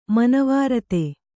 ♪ manavārate